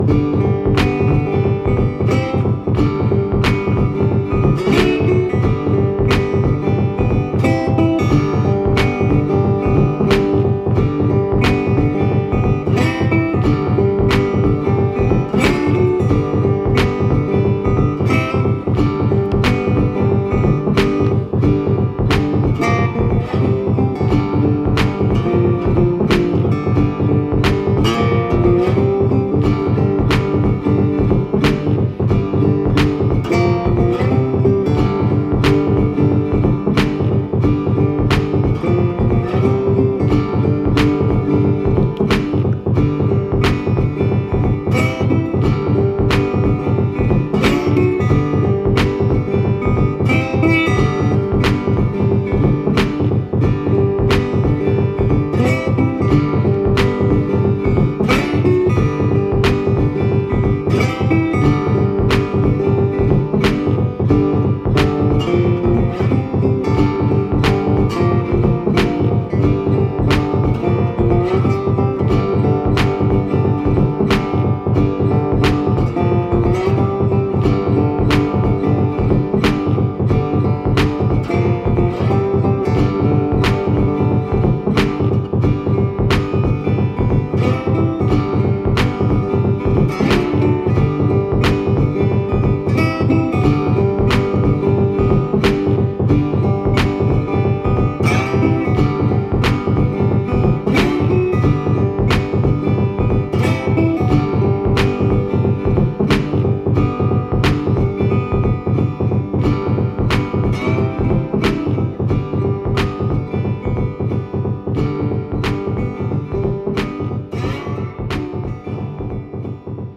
Western guitar with Kaossilator Looper.